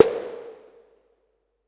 Vermona Snare 01.wav